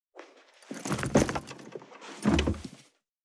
445鞄を振る,荷物を振る,荷物運ぶ,走る,
効果音